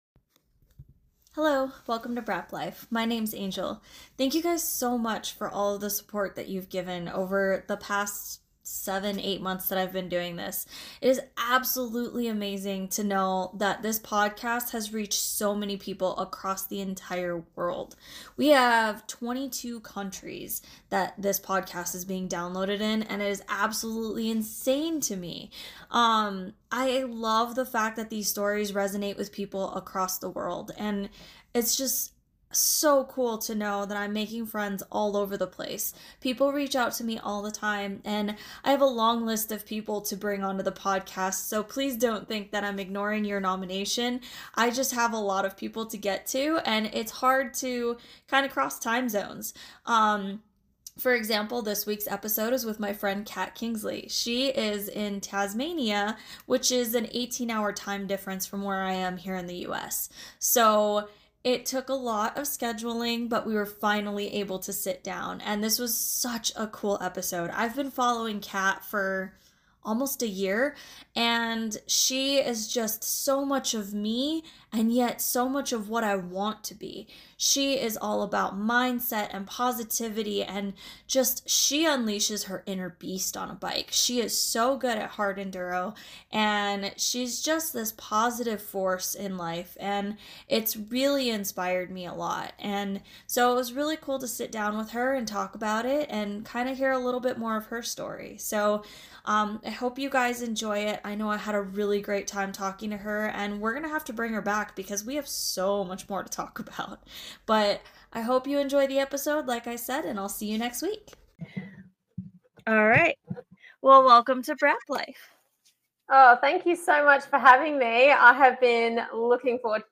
Check out this fun conversation to learn a bit more about him, his family life and of course the Yamaha stuff!